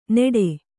♪ neḍe